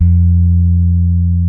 F2 1 F.BASS.wav